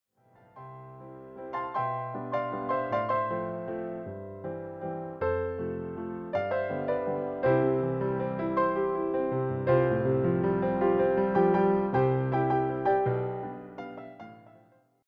all reimagined as solo piano pieces.
just the piano, no vocals, no band.